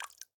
drip_water15.ogg